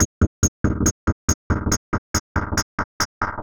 tx_perc_140_chippingaway.wav